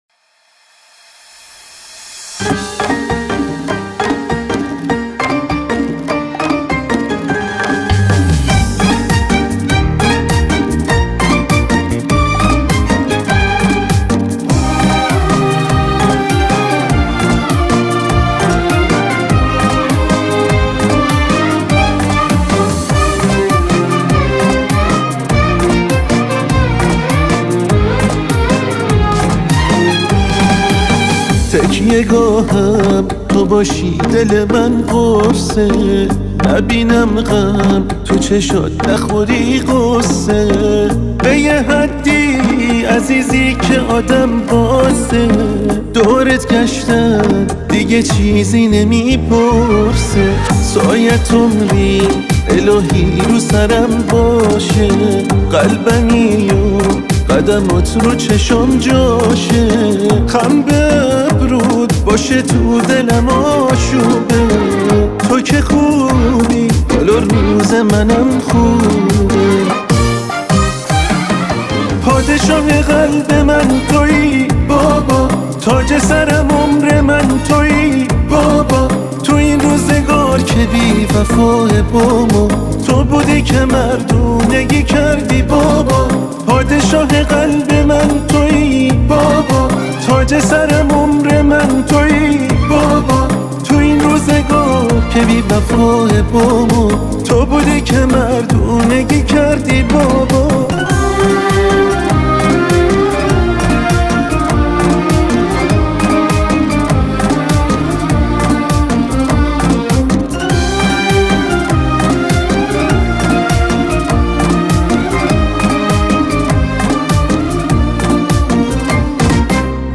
شاد و پر انرژی